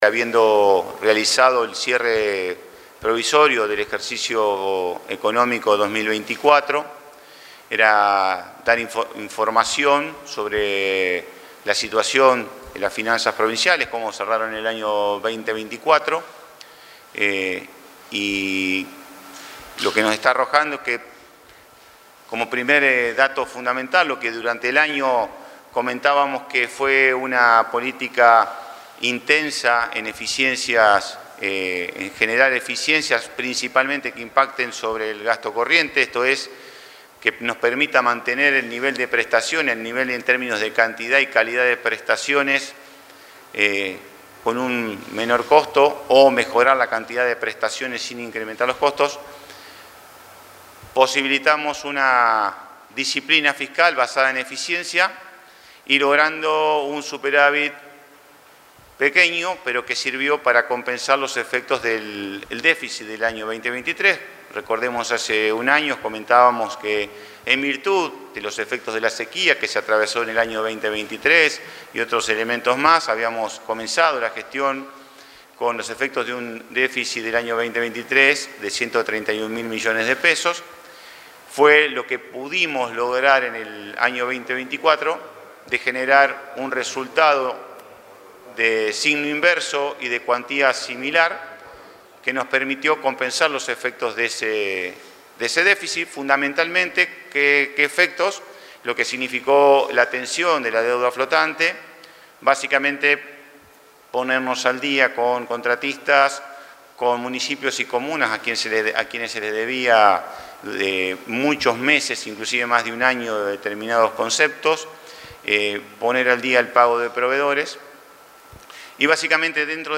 Fragmento de la conferencia de prensa del ministro Olivares